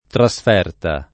[ tra S f $ rta ]